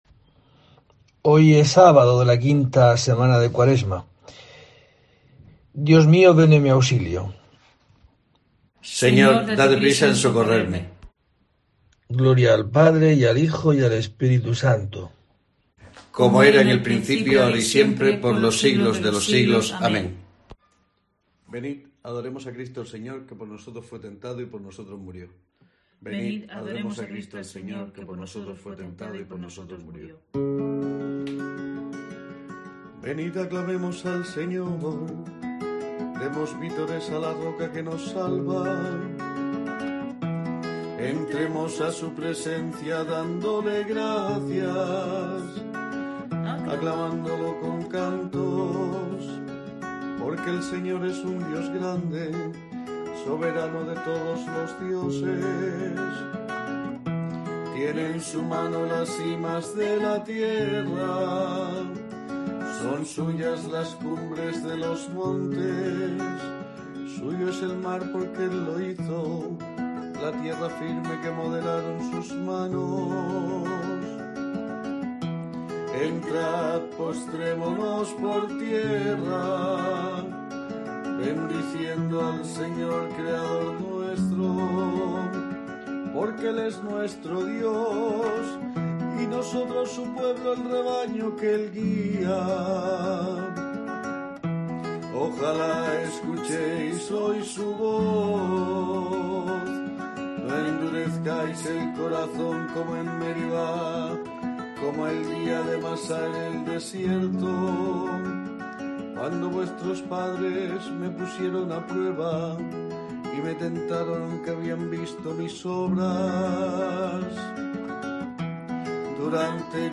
COPE te ayuda a prepararte para la Semana Santa con el rezo diario de los Laudes
desde la iglesia de Santa Eulalia la oración